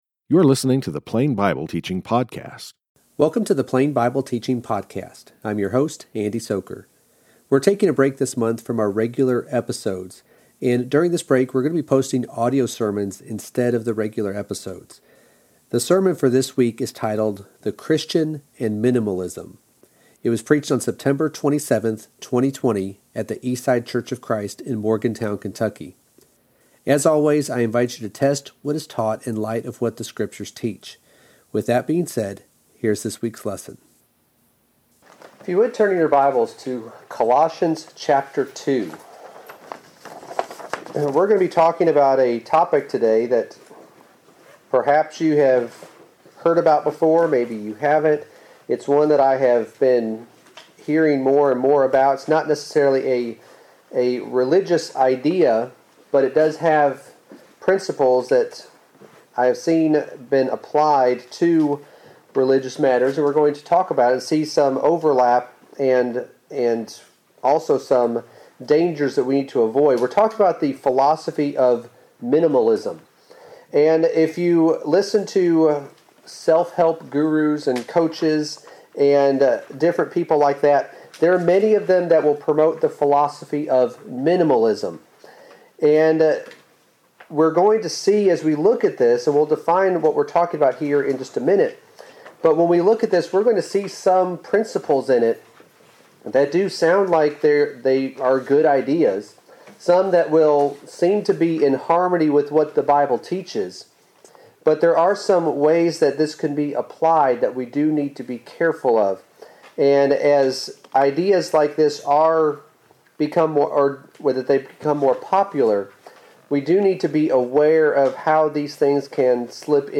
The sermon for today is titled, The Christian and Minimalism , and was preached on September 27, 2020 at the Eastside church of Christ in Morgantown, KY.
the-christian-and-minimalism-sermon-44.mp3